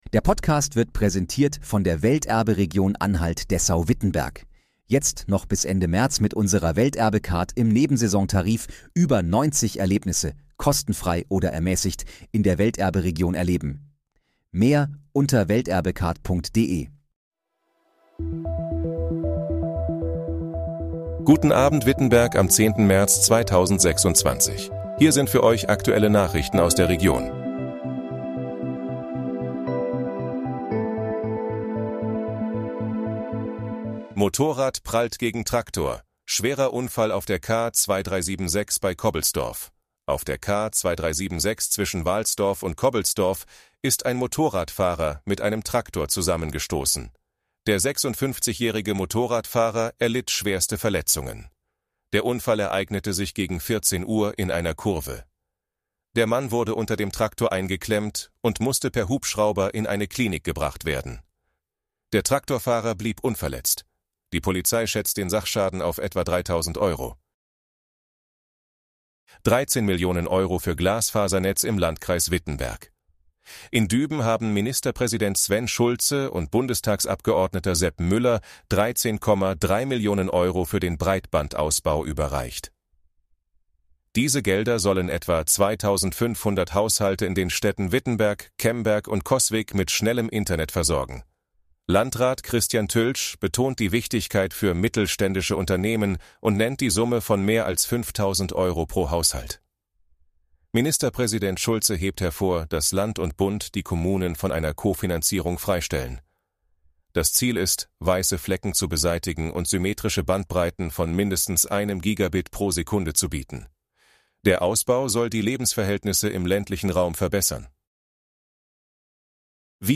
Guten Abend, Wittenberg: Aktuelle Nachrichten vom 10.03.2026, erstellt mit KI-Unterstützung